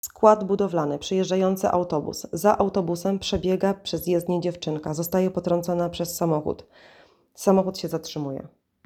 Nagranie audio Audiodeskrypcja filmu
Opis nagrania: audiodeskrypcja filmu.